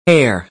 us_phonetics_sound_hair_2023feb.mp3